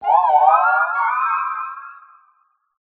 calyrex_ambient.ogg